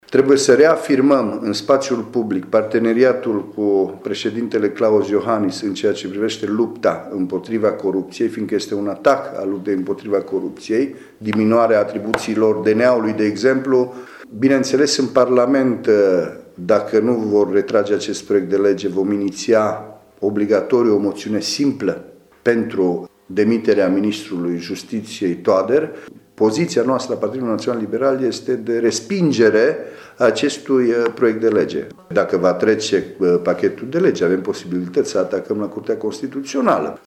PNL pregătește mai multe căi de a contracara propunerile controversate ale ministrului justiției. Guvernații național-liberali sunt pregătiți chiar să depună o moțiune simplă, a mai arătat senatorul PNL de Mureș, Cristian Chirteș: